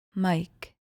Pronounced: MIKE